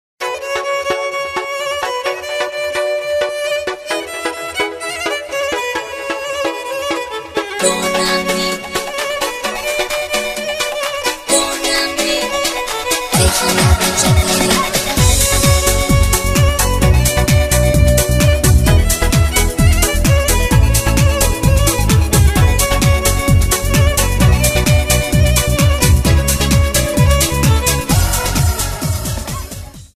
• Качество: 320, Stereo
красивые
скрипка